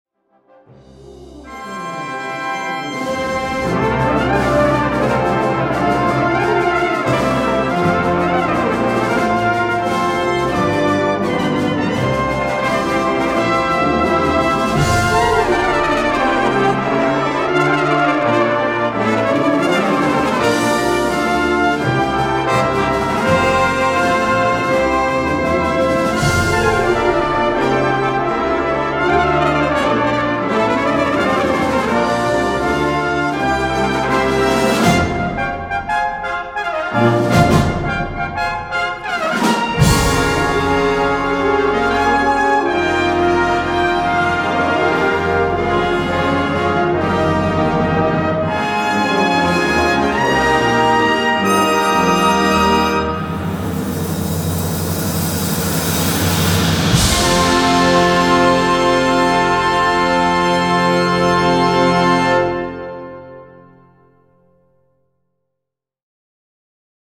Brass Band